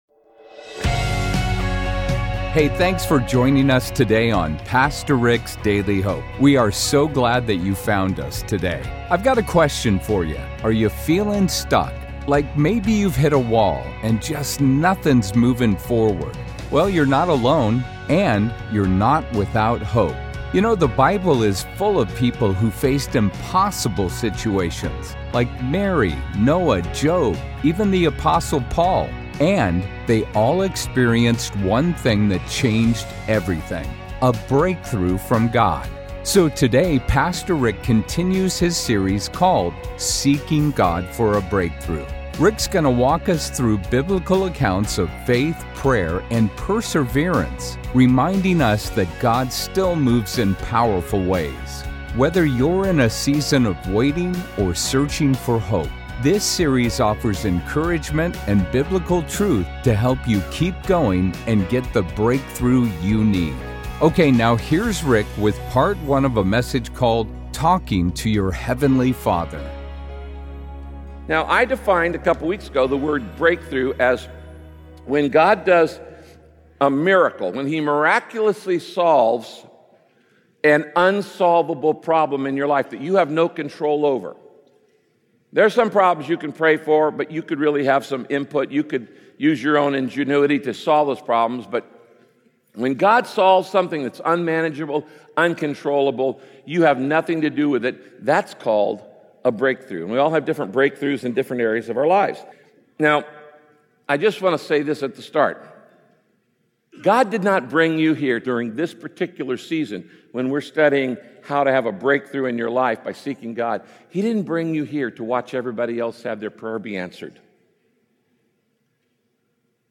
The fact is, prayer can often be confusing. In this message, Pastor Rick teaches us how to not only pray with more confidence, but to actually enjoy it!